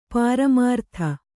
♪ pāramārtha